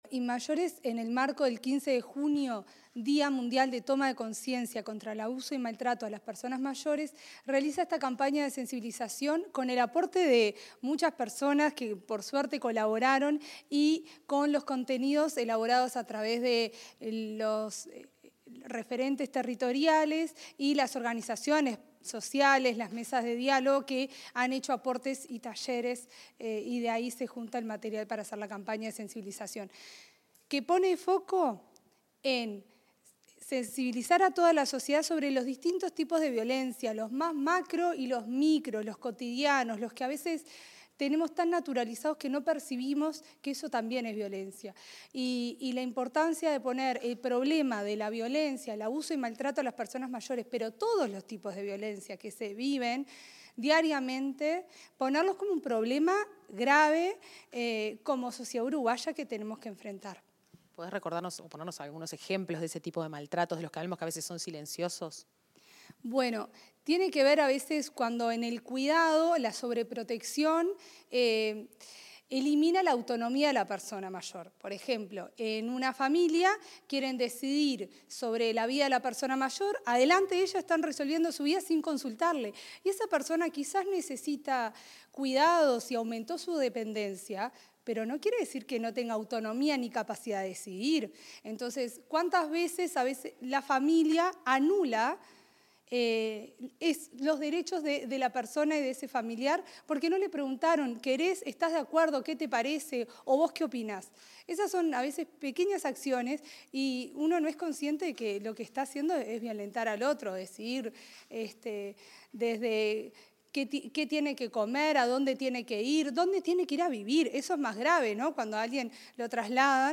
Declaraciones de directora de Inmayores, Marianela Larzábal
Declaraciones de la directora del Instituto Nacional de las Personas Mayores (Inmayores), Marianela Larzábal, sobre campaña de sensibilización contra